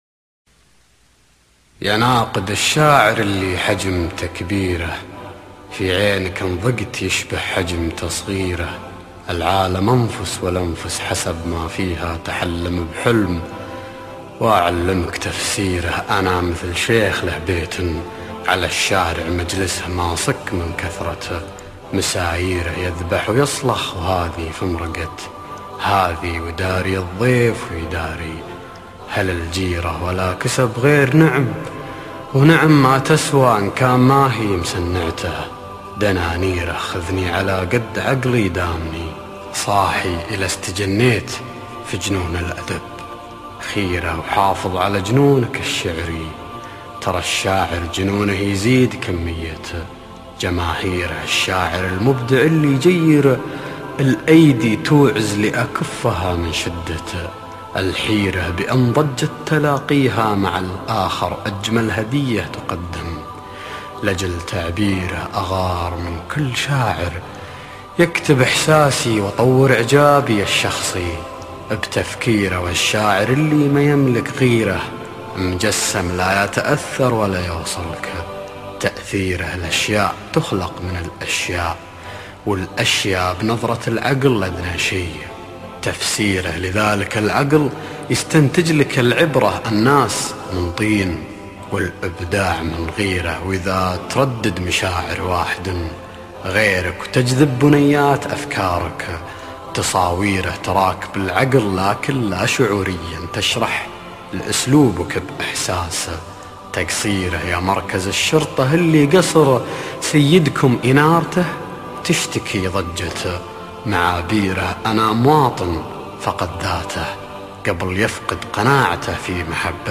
ياناقد الشاعر - القاء سعد علوش